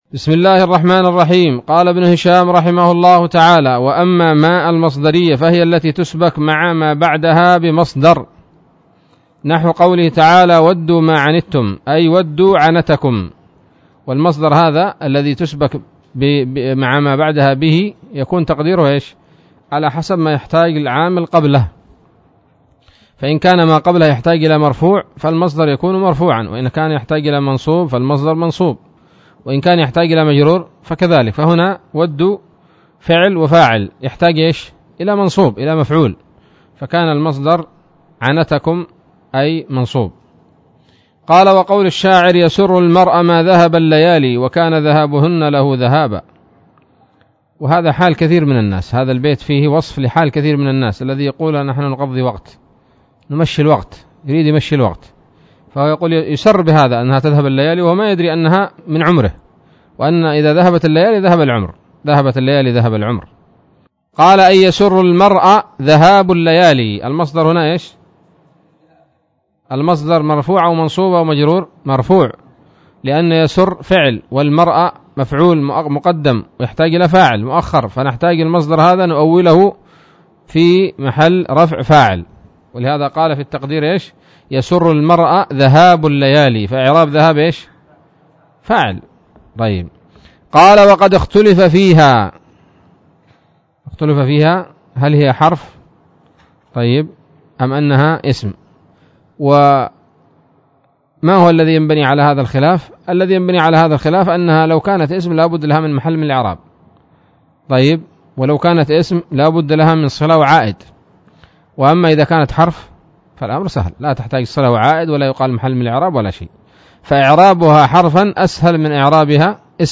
الدرس الخامس عشر من شرح قطر الندى وبل الصدى